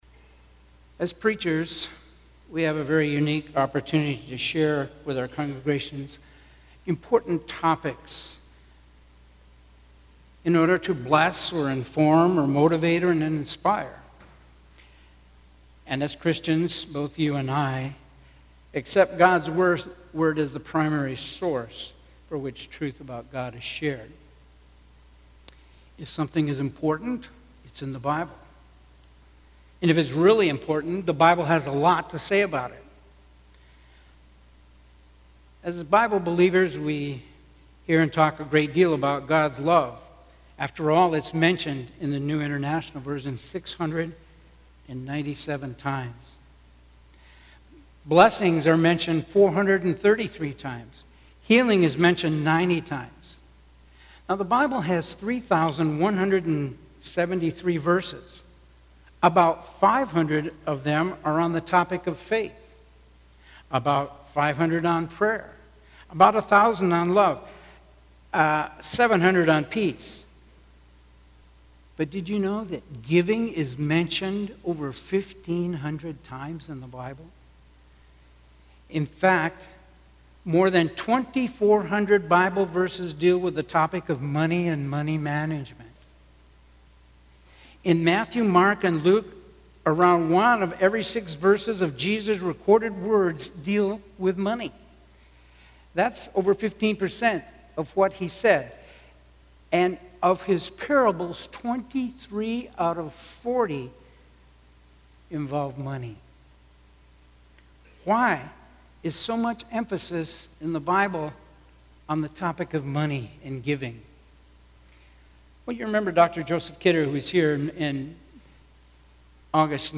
SERMONS 2013